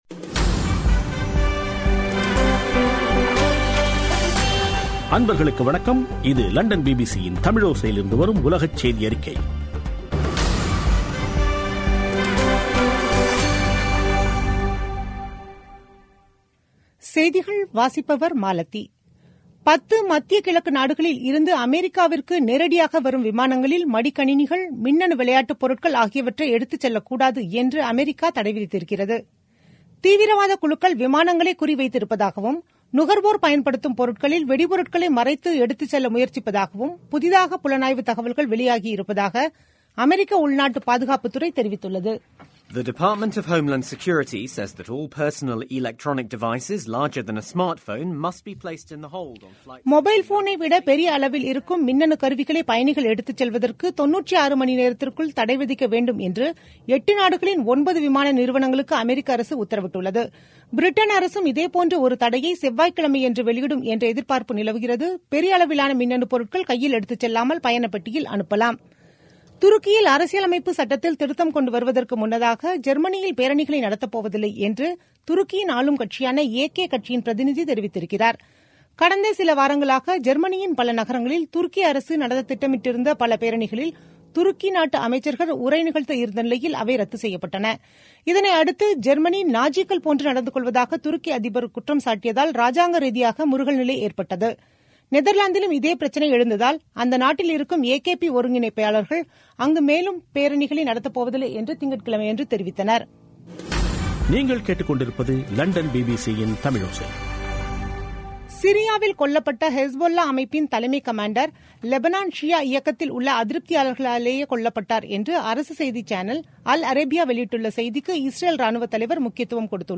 பிபிசி தமிழோசை செய்தியறிக்கை (21/03/2017)